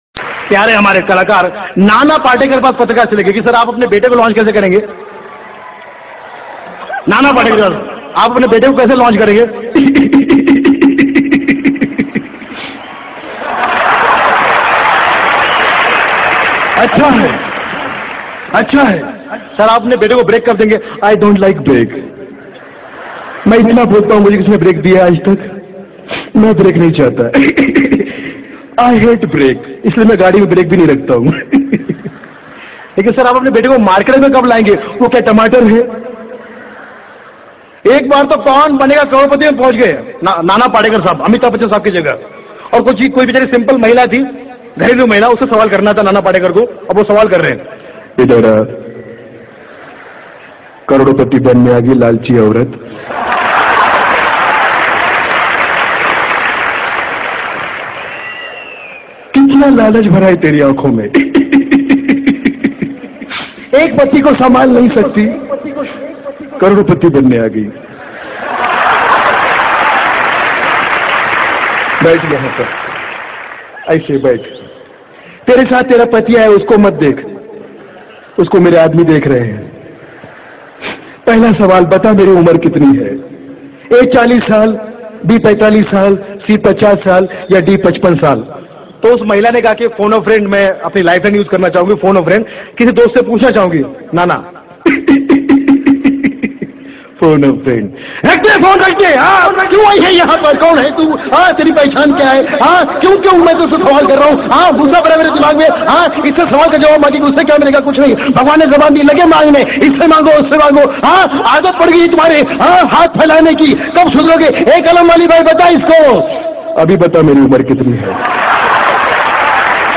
Funny Mimicry Voice